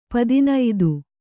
♪ padinaidu